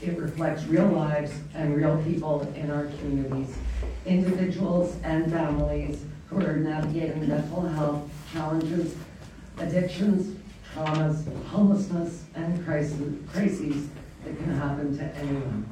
A grand opening ceremony for the Renfrew County Mesa HART Hub was held on Thursday, February 5th